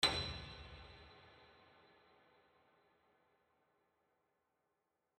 piano-sounds-dev
b6.mp3